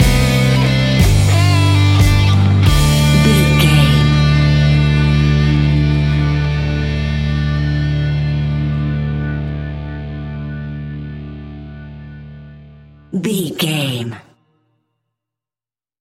Epic / Action
Fast paced
Mixolydian
D
hard rock
blues rock
rock instrumentals
Rock Bass
heavy drums
distorted guitars
hammond organ